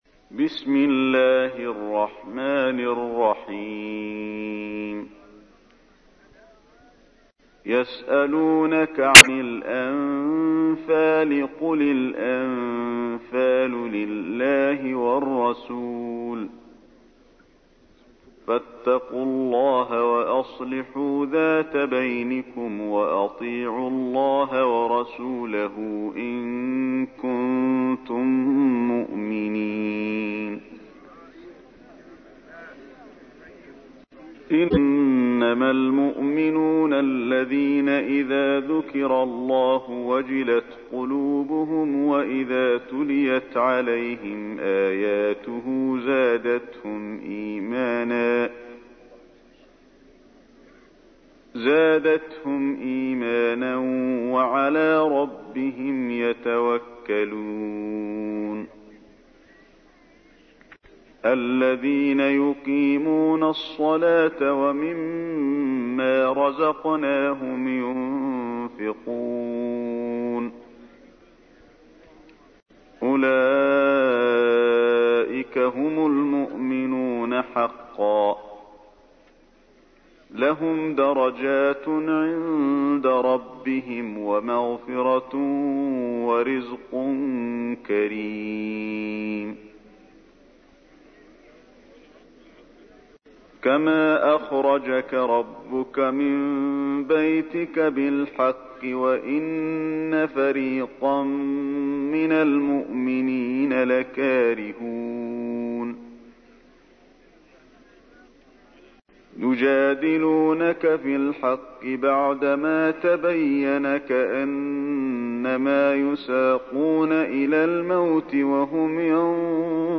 تحميل : 8. سورة الأنفال / القارئ علي الحذيفي / القرآن الكريم / موقع يا حسين